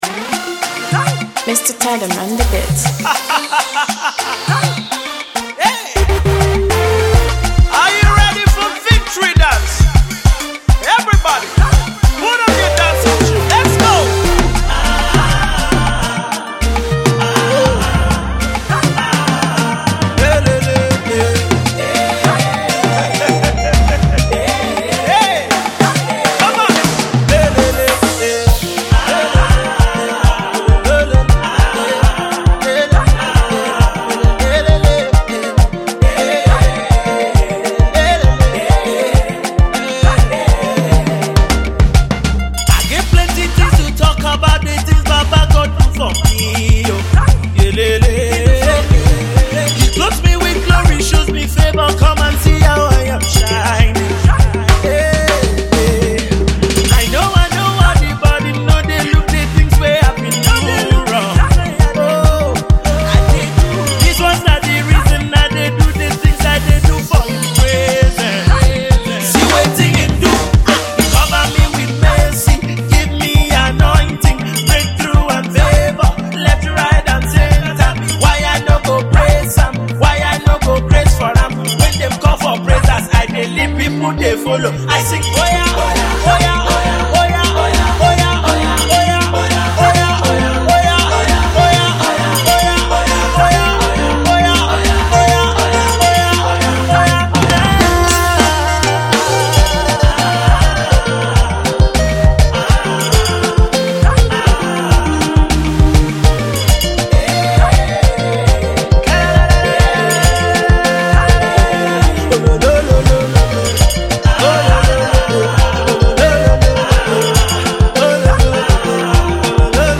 gospel twin duo
dance track
Nigerian gospel song